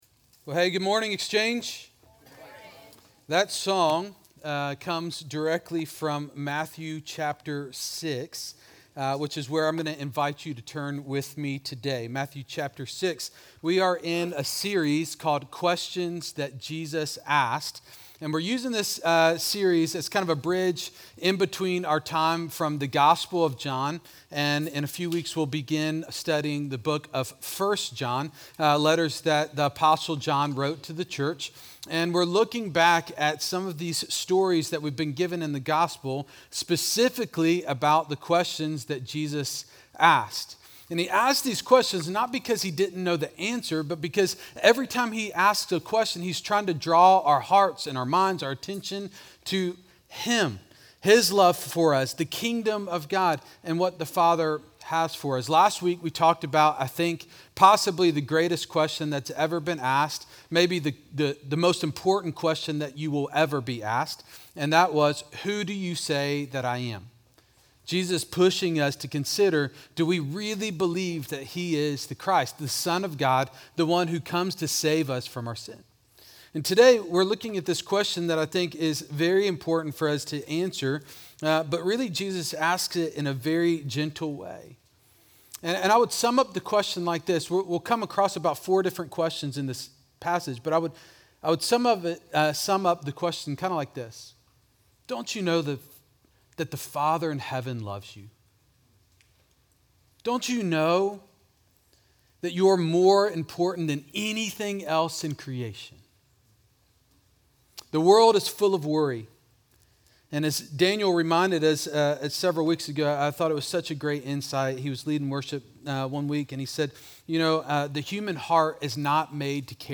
Exchange Church Sermons Don't You Know?